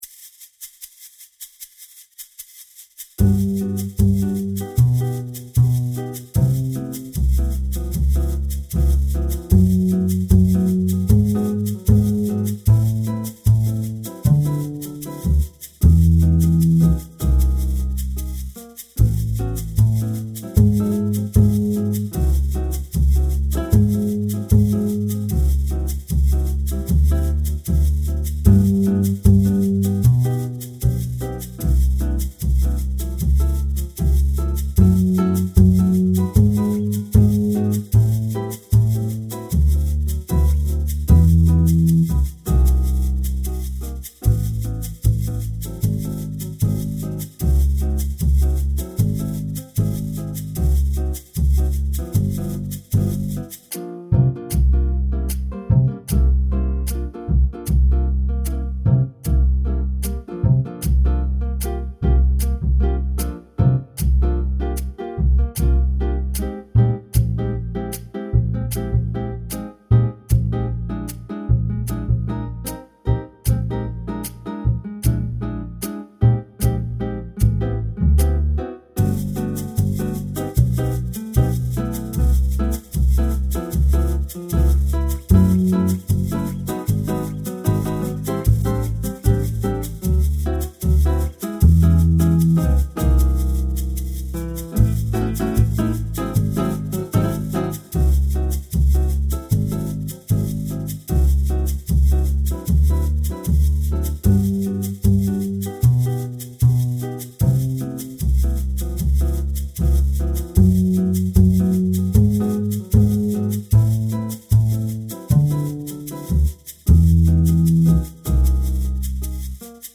Play along tracks at 76 bpm and 92 bpm